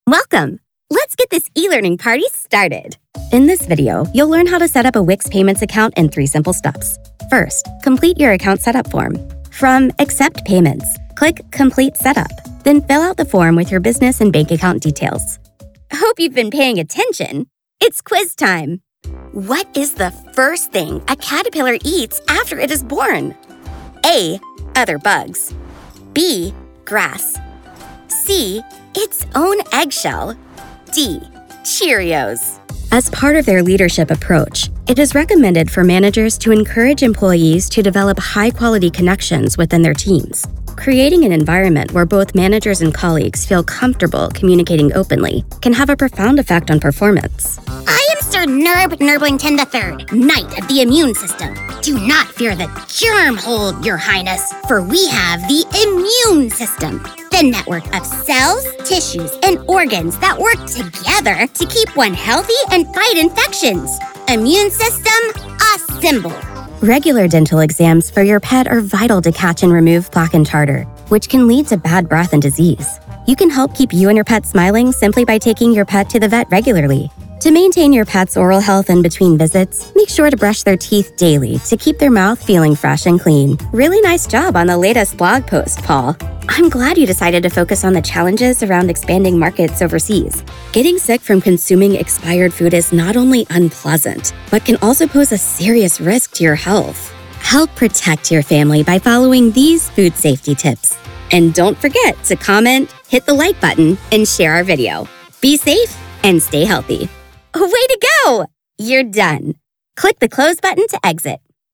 Female
She brings charm, clarity, and versatility to every read—from conversational and friendly to quirky, energetic, or sincere.
E-Learning
Clear, Friendly Learning
Words that describe my voice are Youthful, Conversational, Expressive.